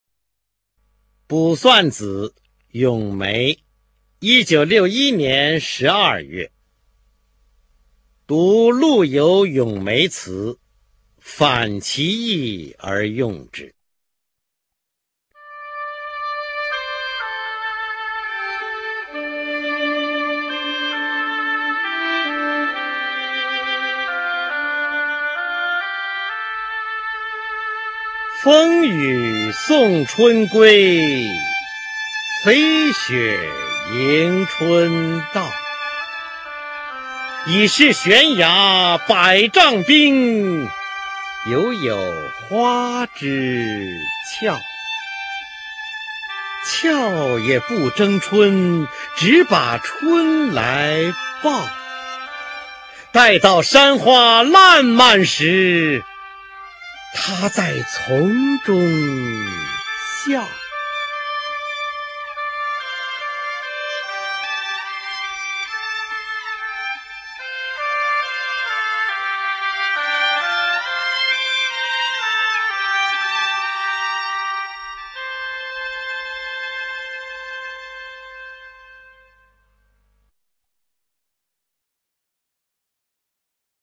毛泽东《卜算子·咏梅》原文、译文、赏析（含朗读）